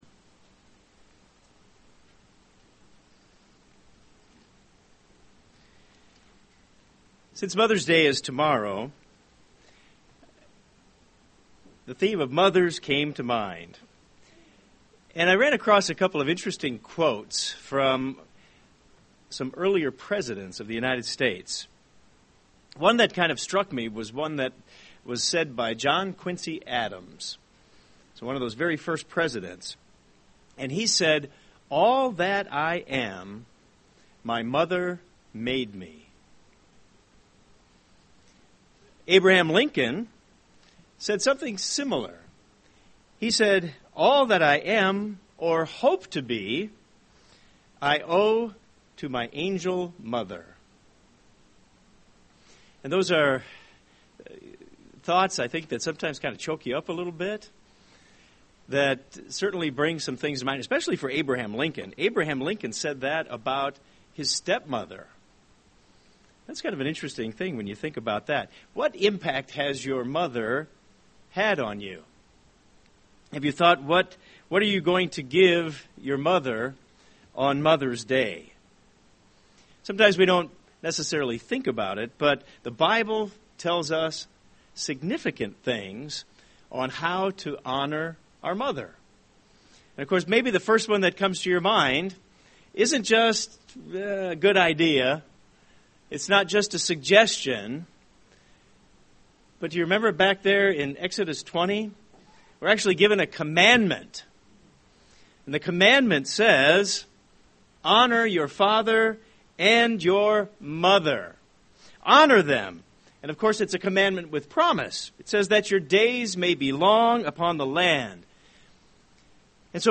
In this sermon we learn how to honor our physical mother as well as our spiritual mother who is the church.